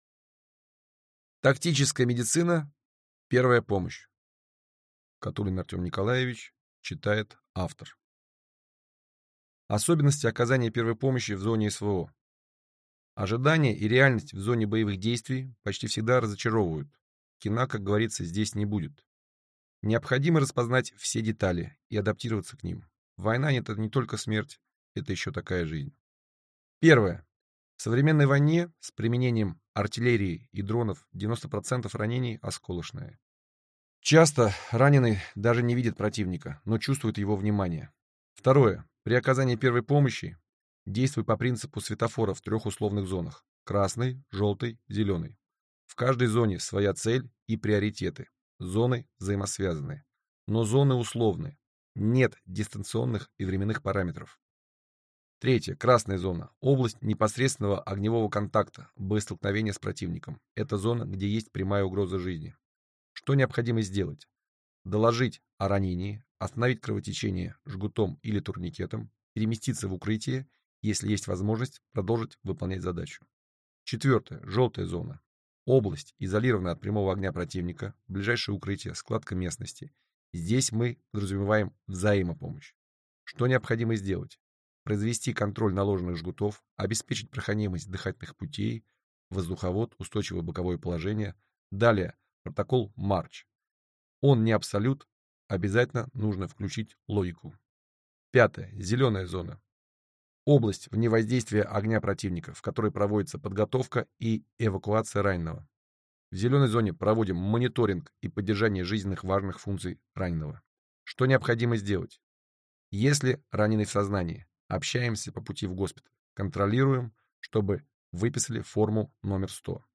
Аудиокнига Тактическая медицина. Первая помощь в экстремальных условиях | Библиотека аудиокниг